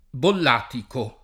bollatico
bollatico [ boll # tiko ]